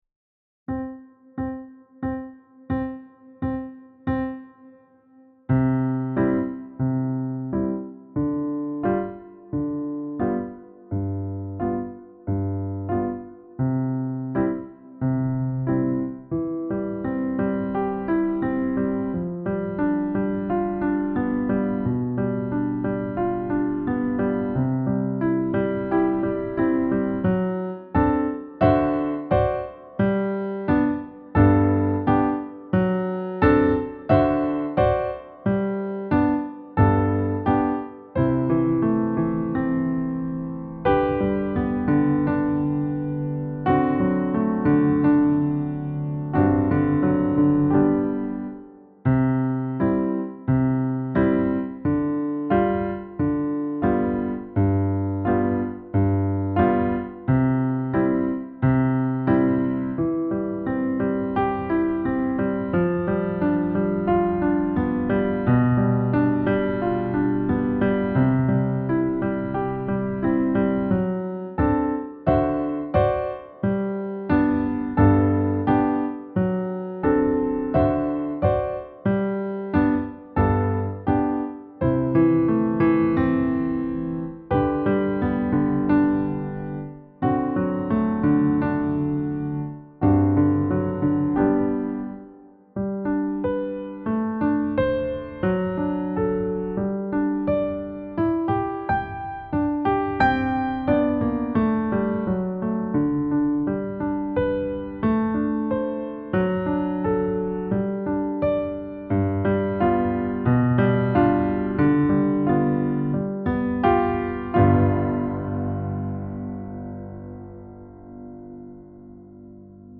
Piano Accompaniment Track